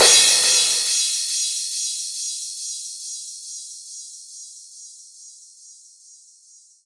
VTDS2 Song Kit 06 Pitched If You Know Crash.wav